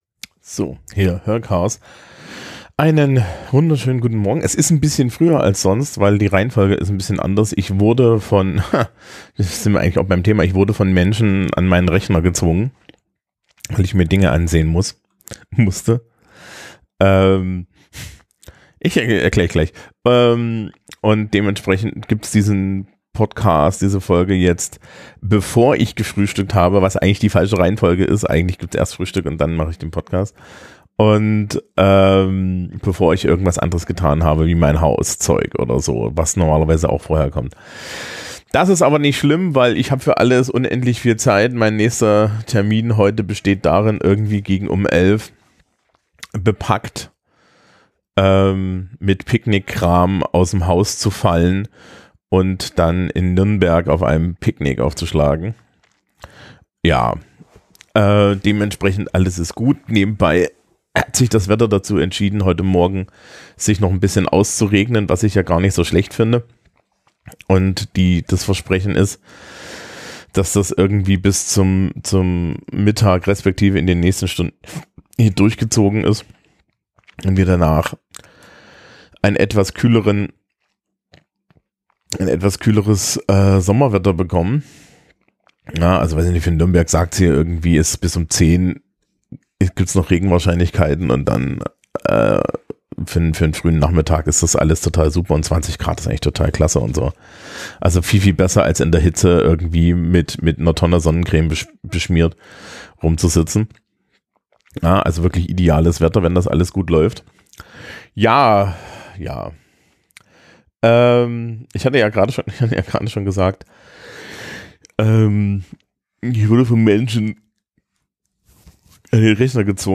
Heute in philosophisch und rambly, mit Gähnen und ohne Frühstück...